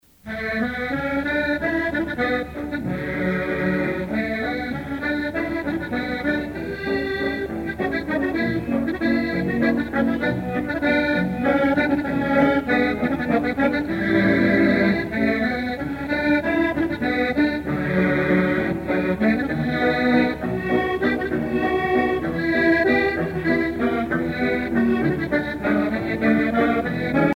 valse musette